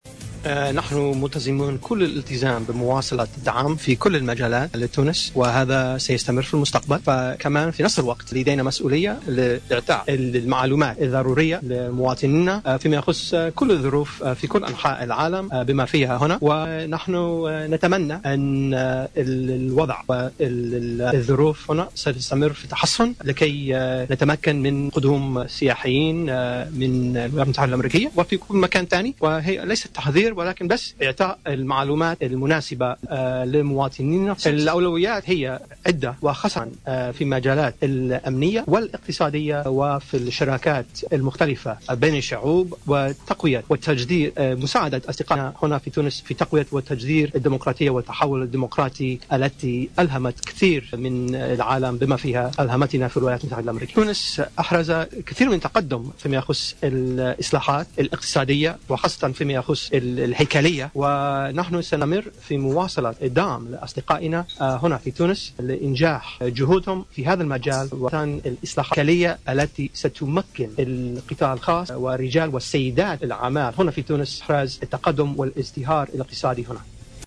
نفى سفير أمريكا الجديد بتونس دانيال روبن شتاين خلال أول ندوة صحفية عقدها اليوم الجمعة 06 نوفمبر 2015 بالسفارة الأمريكية أن تكون بلاده قد حذرت مواطنيها من السفر إلى تونس.